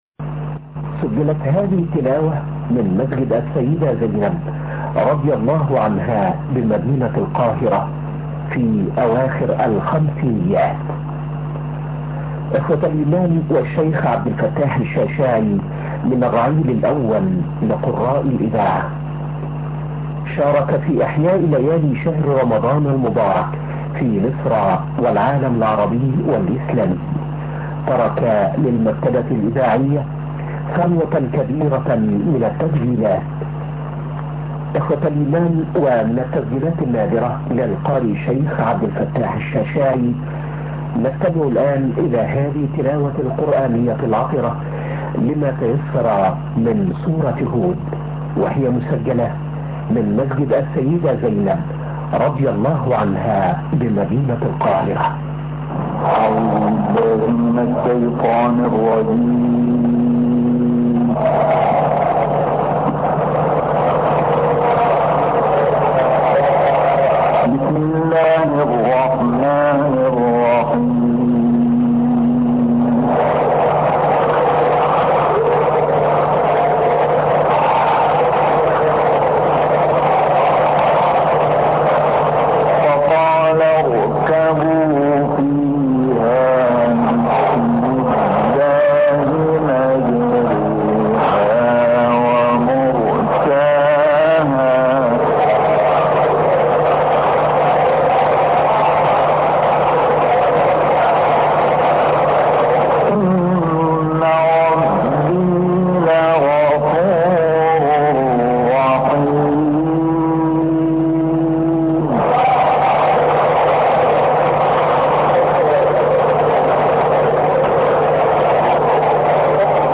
گروه شبکه اجتماعی: تلاوت آیاتی از سوره هود، سوره فاطر و سوره بینه با صوت عبدالفتاح شعشاعی را می‌شنوید.